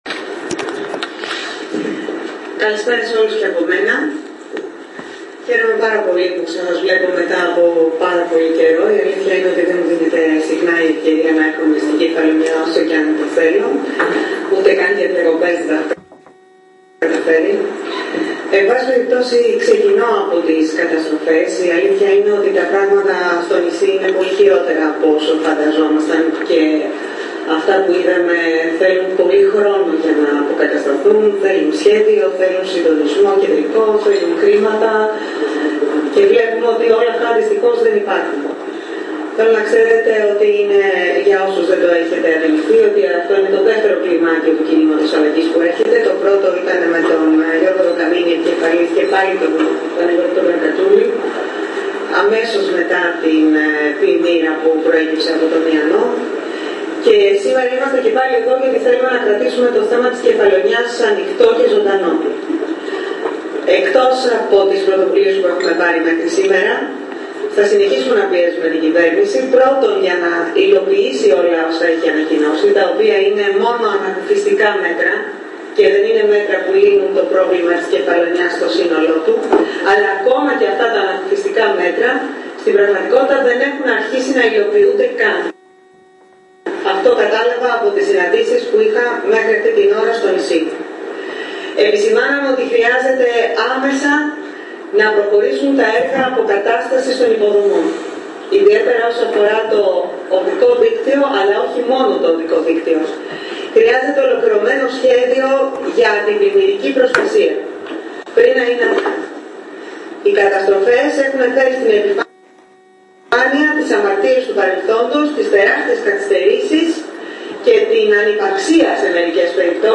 Κατόπιν στη μεγάλη αίθουσα του Επιμελητηρίου η κ. Φώφη Γεννηματά απευθύνθηκε προς τα μέλη και στελέχη του Κινήματος Αλλαγής.